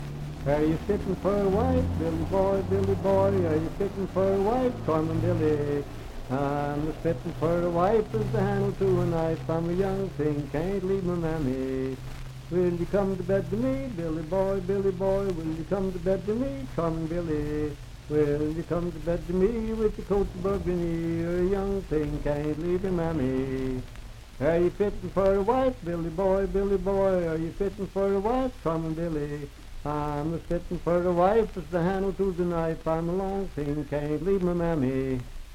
Unaccompanied vocal music
Dance, Game, and Party Songs, Children's Songs
Voice (sung)
Franklin (Pendleton County, W. Va.), Pendleton County (W. Va.)